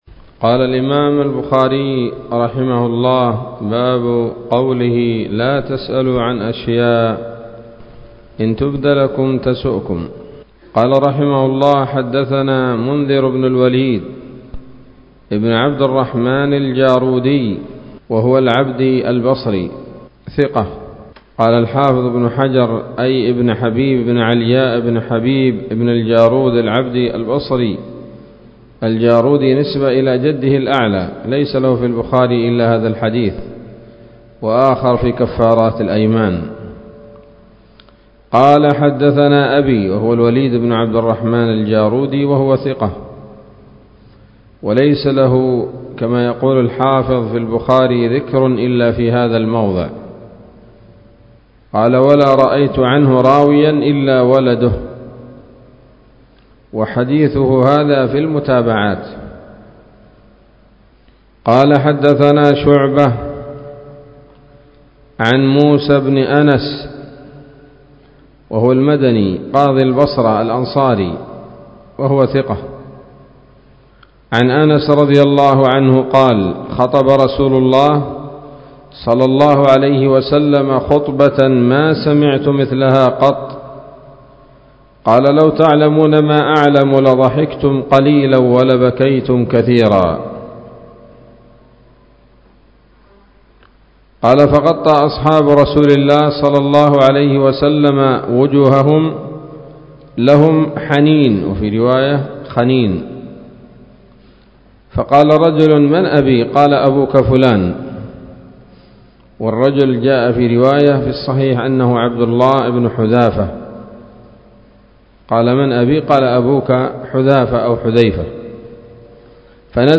الدرس الخامس والتسعون من كتاب التفسير من صحيح الإمام البخاري